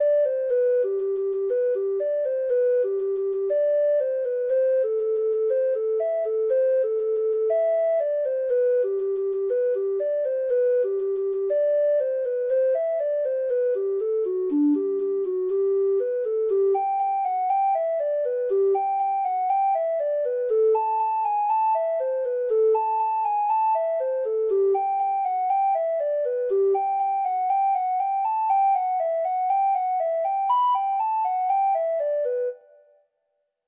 Traditional Trad. The Boys of Ballinchalla (Irish Folk Song) (Ireland) Treble Clef Instrument version
Traditional Music of unknown author.
Irish